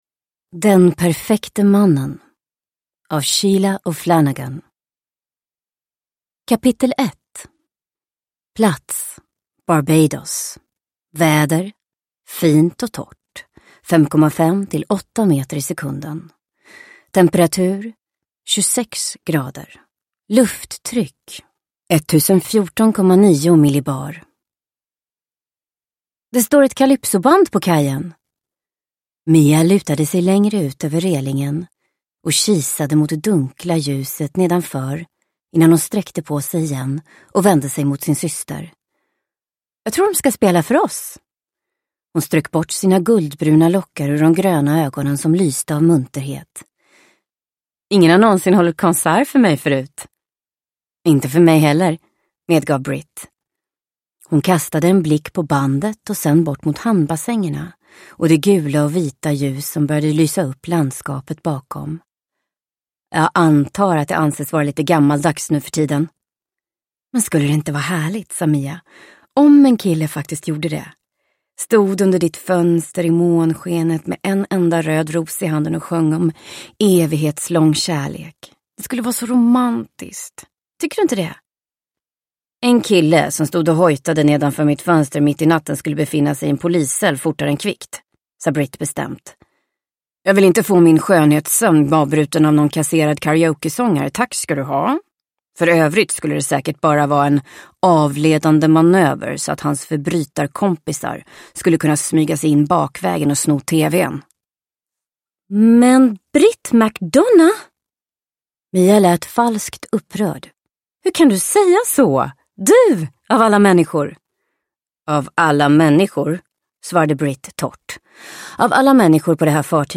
Den perfekte mannen – Ljudbok – Laddas ner